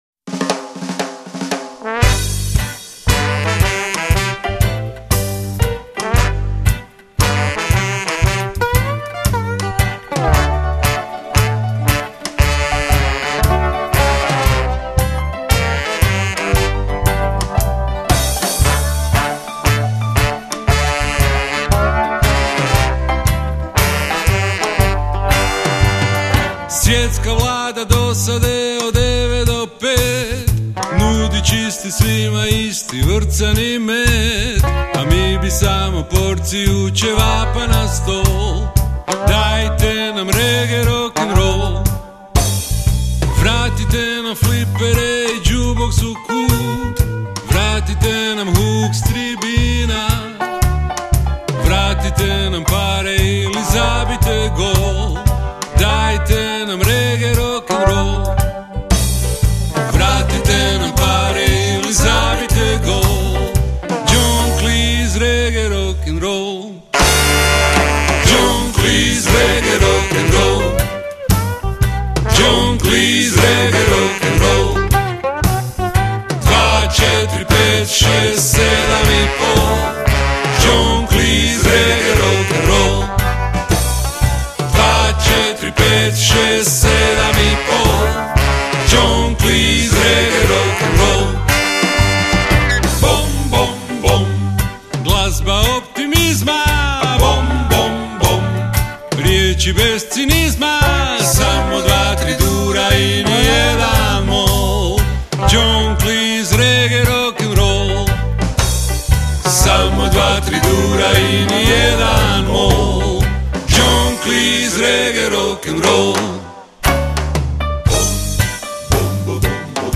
gitara, banjo, glas
saxovi
truba
trombon